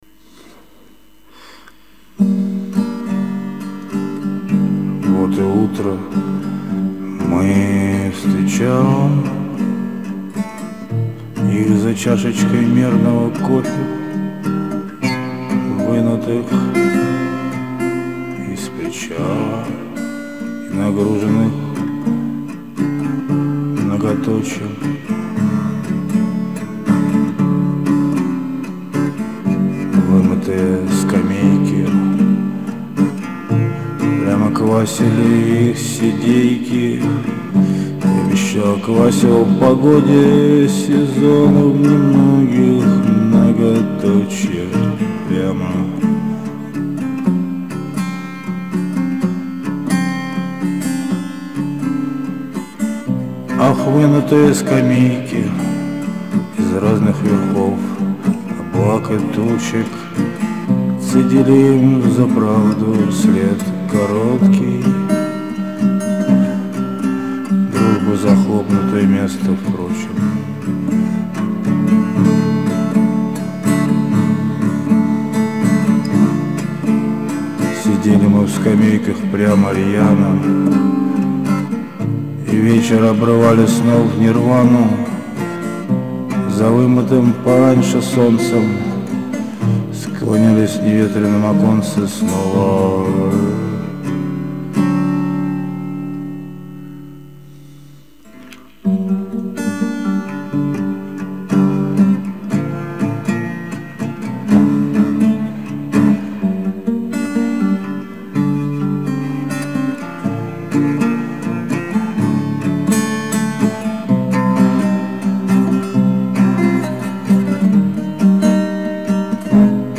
песню записал сейчас